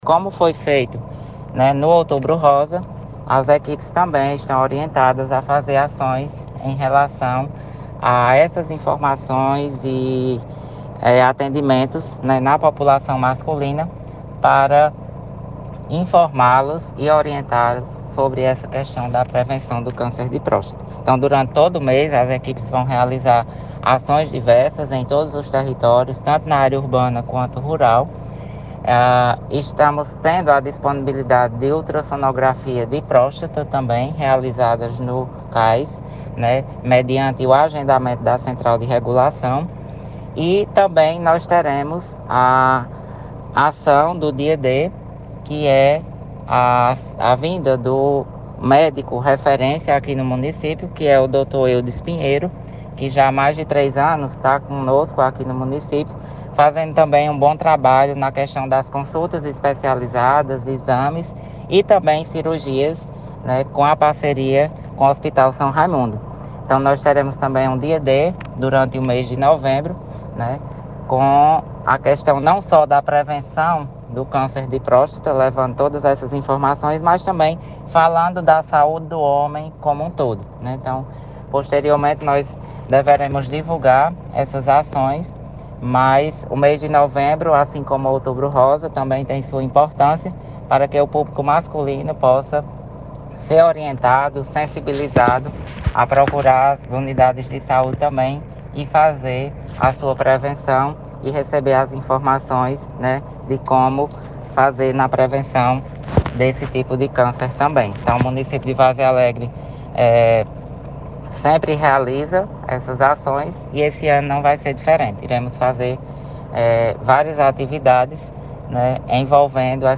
O secretário municipal de Saúde, Ivo Leal diz a reportagem como será a campanha no município de Várzea Alegre.